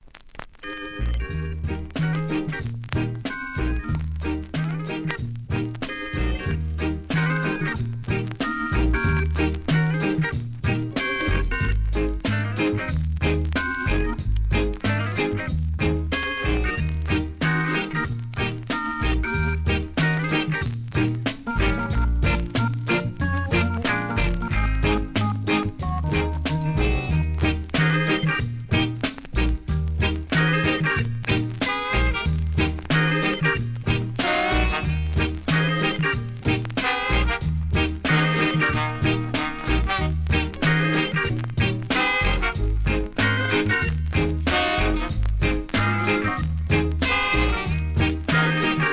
REGGAE
ROCK STEADY SOUL!!!